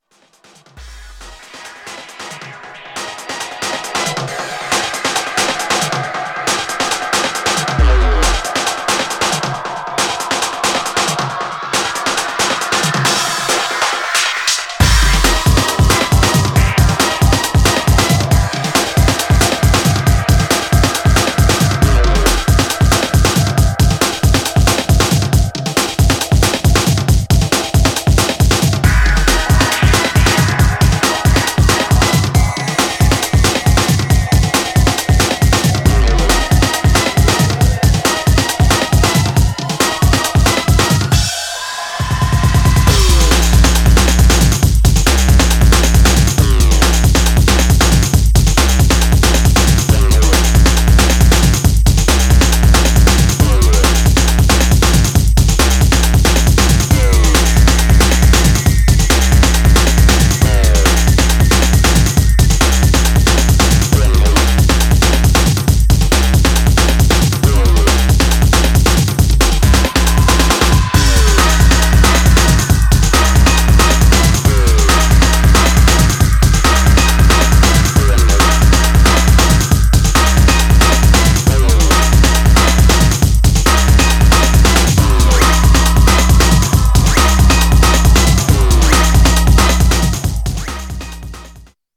Styl: Dub/Dubstep, Breaks/Breakbeat